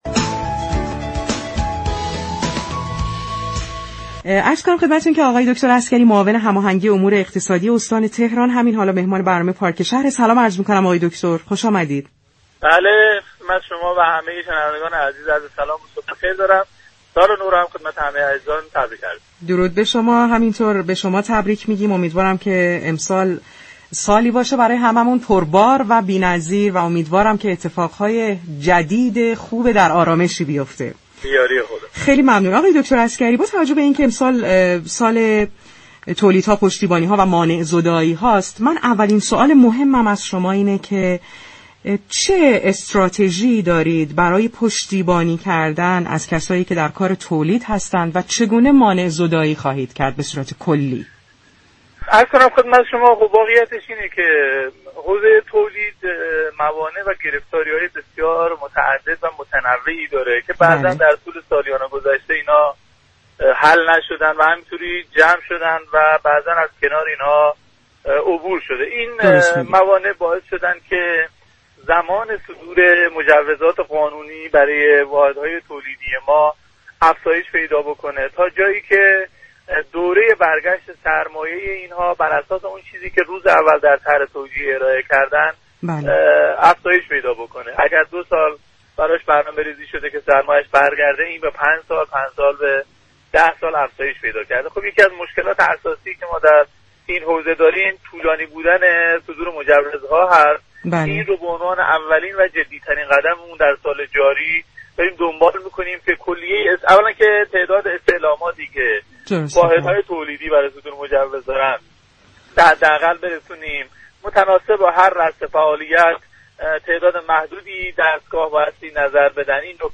عسگری در ادامه این گفتگوی رادیویی با اشاره به تعهدات تولیدكنندگان به بانك ها خاطرنشان كرد: تولیدكنندگان باید متعهد به تعهدات خود باشند تا مشخص شود ایراد كار از طرف تولیدكننده یا دستگاه های اجرایی است.